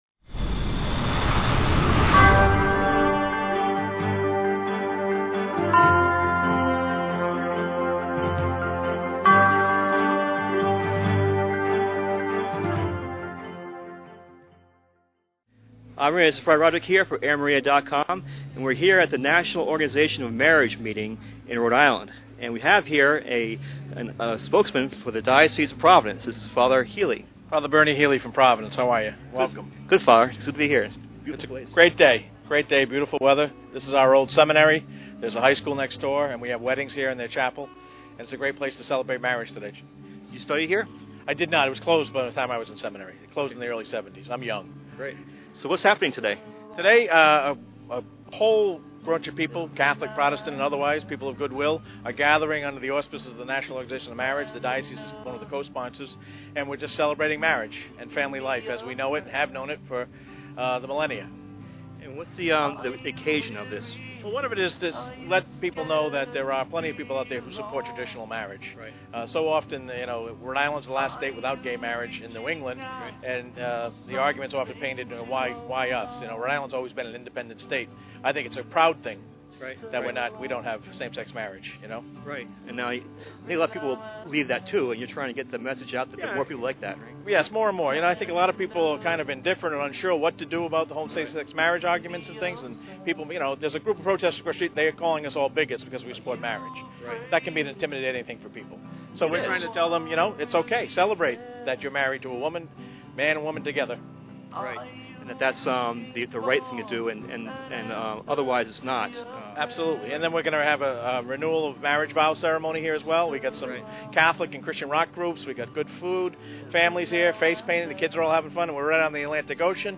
On August 16, a Sunday, the RI Chapter of National Organization for Marriage (NOM) in conjunction with the Diocese of Providence held their first annual Celebrate Marriage and Family Day at the Aldrich Mansion in West Warwick, RI. It was a smash hit with hundreds of couples and families from RI and beyond coming to celebrate marriage and even renew their vows.